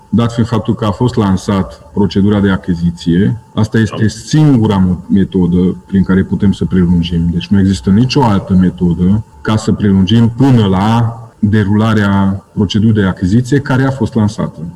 Astfel, operatorul se va stabili prin negociere directă, iar aceasta este singura variantă, a explicat consilierul local Kelemen Marton: